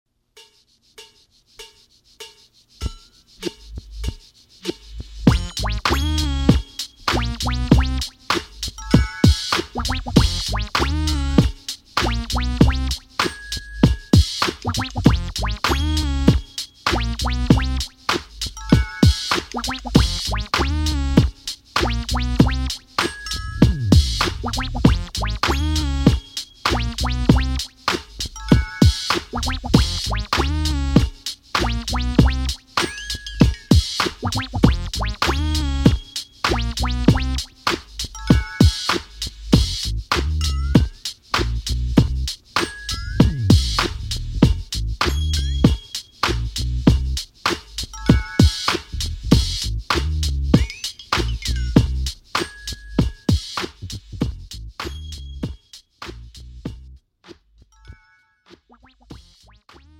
장르 pop 구분 Premium MR